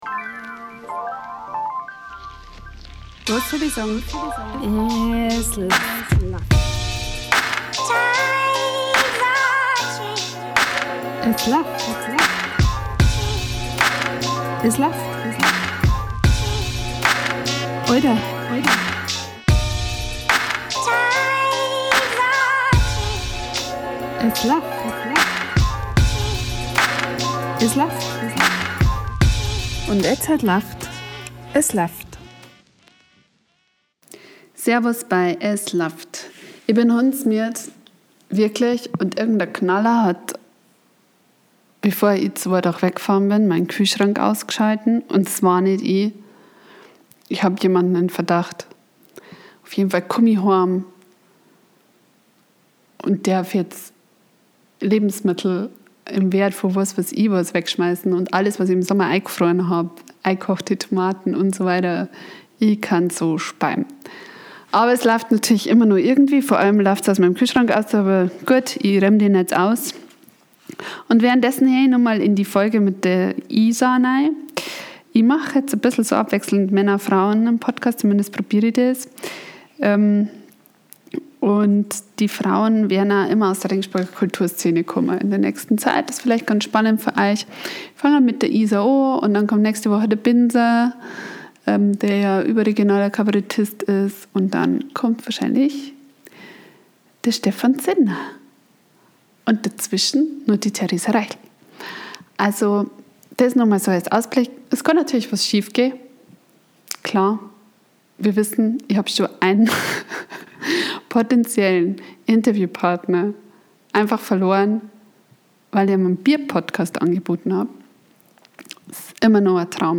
Seit dem Lockdown hat sie auch endlich Ruhe vom wilden Musiker-Mama-Studentenleben und Zeit ihre erste eigene Musik selbst zu produzieren. Ein Hausbesuch kurz vor der ersten Veröffentlichung.